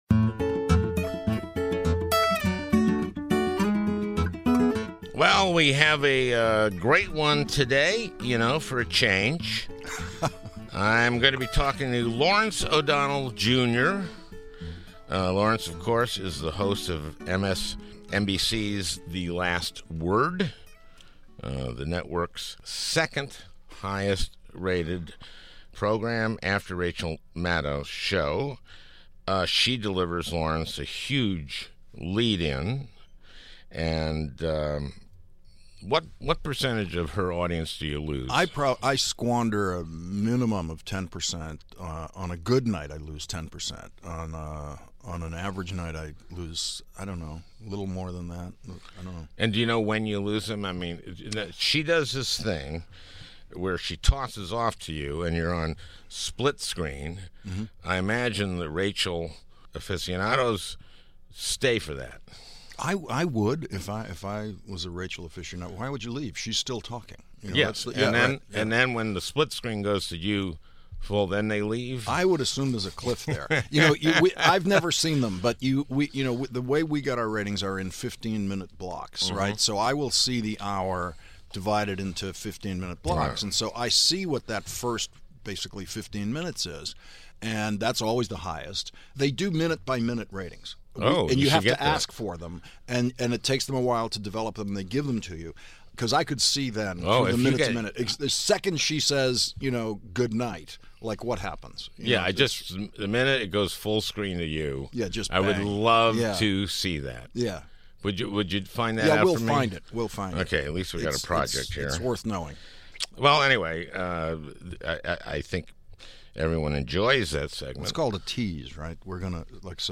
MSNBC's Second Most Popular Host, Lawrence O'Donnell, Is Guest